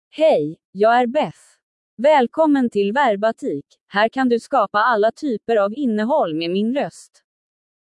BethFemale Swedish AI voice
Beth is a female AI voice for Swedish (Sweden).
Voice sample
Listen to Beth's female Swedish voice.
Female
Beth delivers clear pronunciation with authentic Sweden Swedish intonation, making your content sound professionally produced.